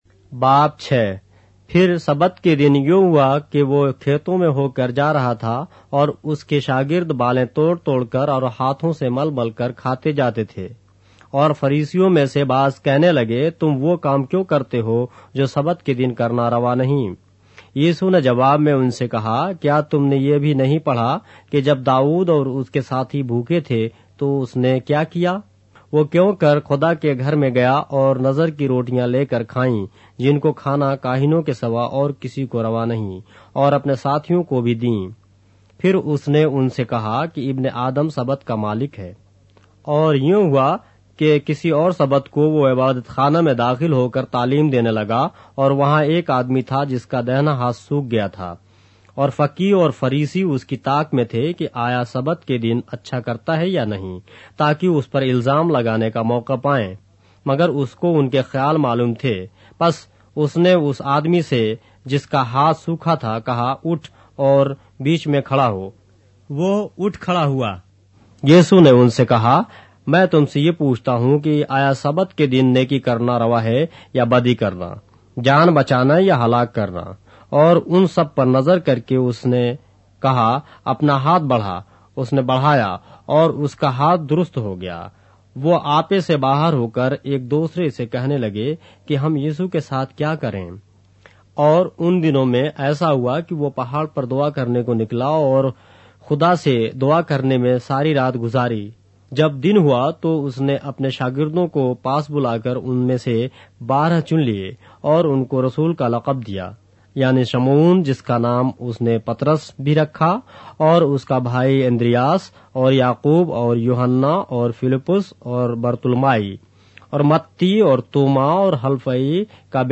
اردو بائبل کے باب - آڈیو روایت کے ساتھ - Luke, chapter 6 of the Holy Bible in Urdu